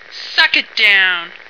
flak_m/sounds/female1/int/F1suckitdown.ogg at df55aa4cc7d3ba01508fffcb9cda66b0a6399f86